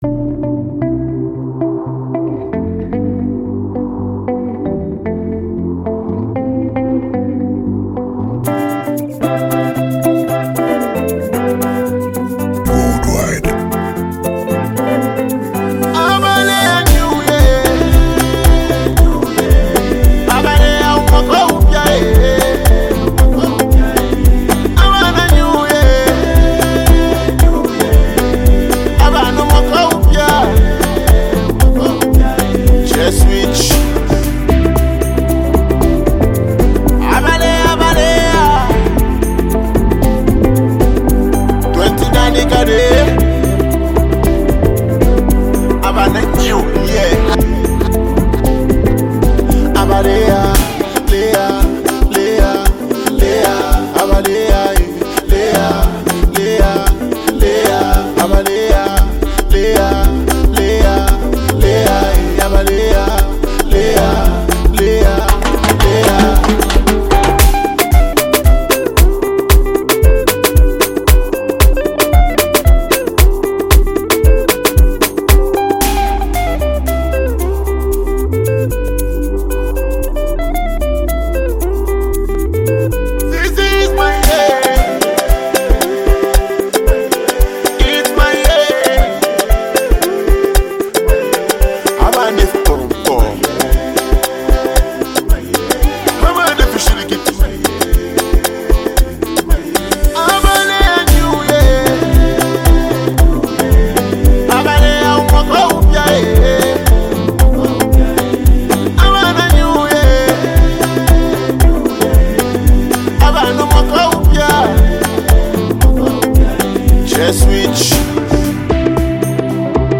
celebratory and culturally grounded song
signature energetic delivery and catchy melodies
With its upbeat rhythm and festive vibe